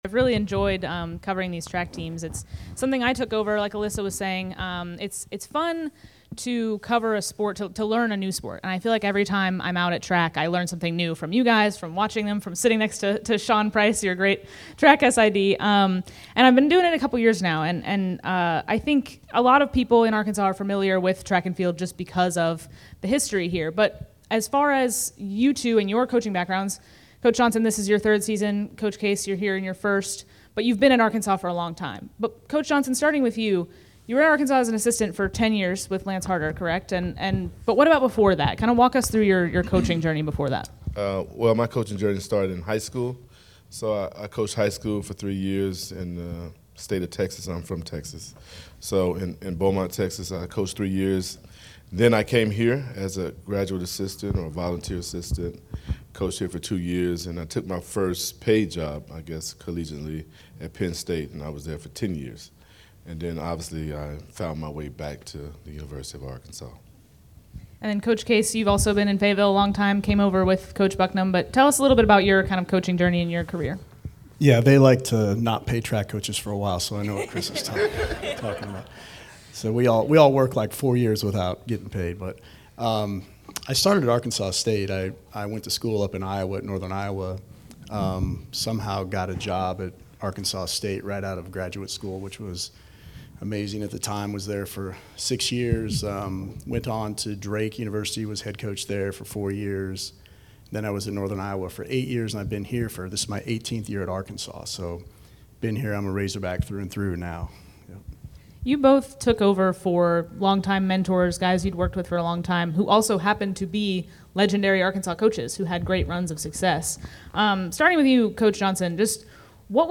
Arkansas Track and Field coaches Q&A